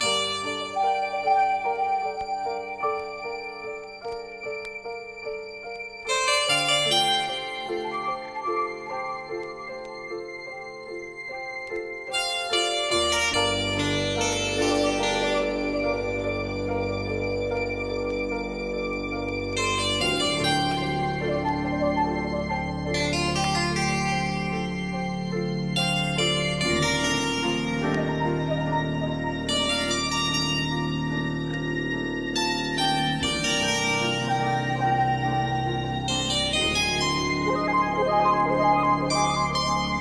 RABBIT HOLE 72BPM - FUSION.wav